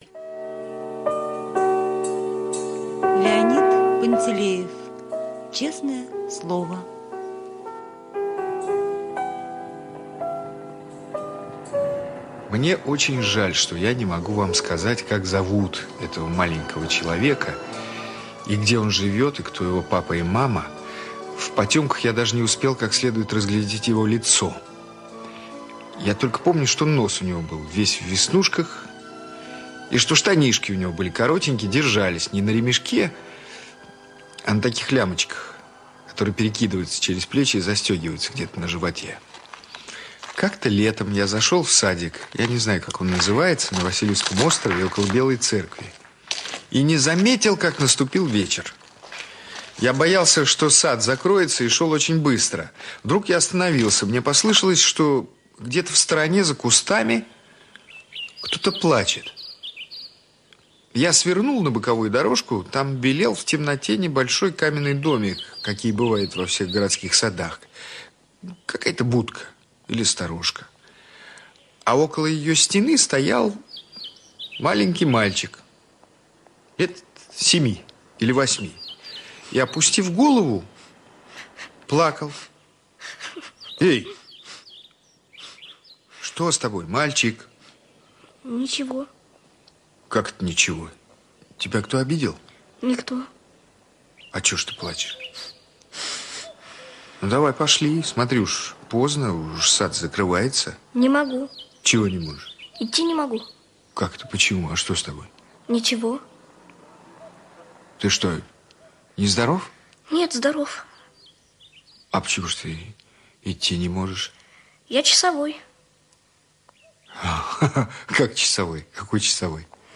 Аудиорассказ «Честное слово»